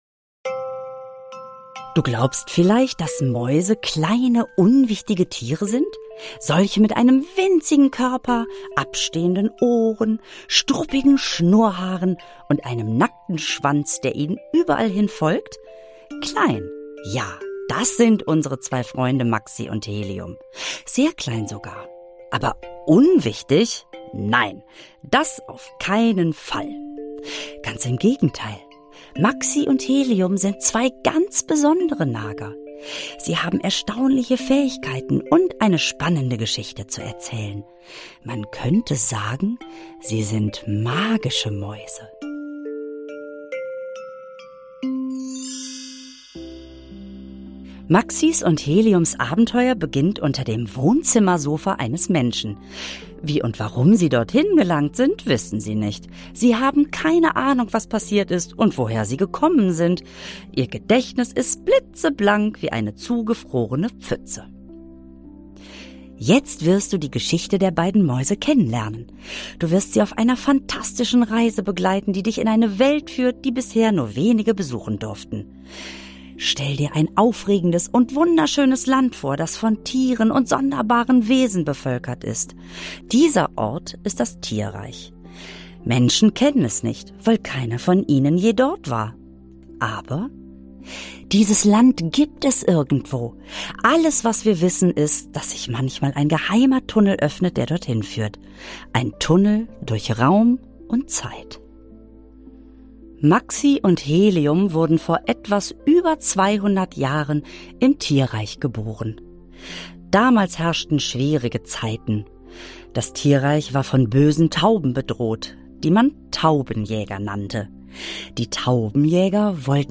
Lisa Feller (Sprecher)